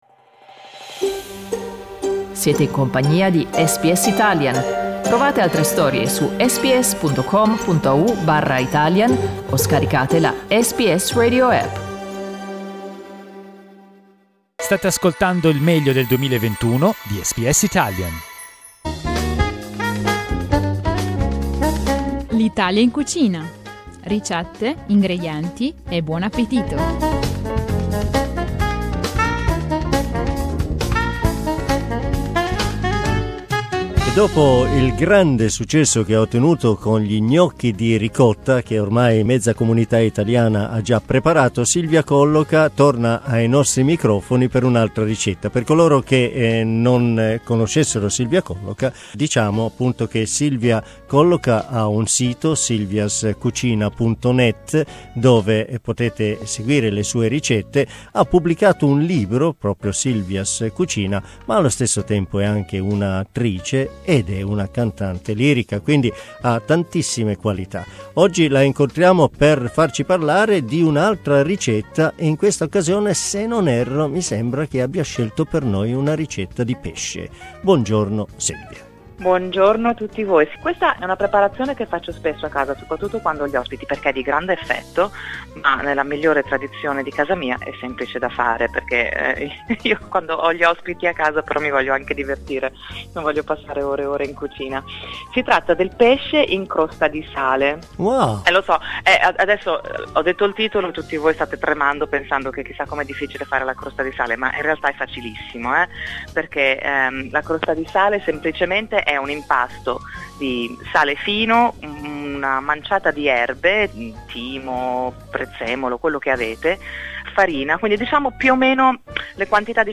Silvia Colloca torna ai microfoni di SBS Italian con una ricetta semplice e veloce da preparare per quando si hanno ospiti a cena e si desidera fare bella figura ma anche godersi il tempo con parenti ed amici.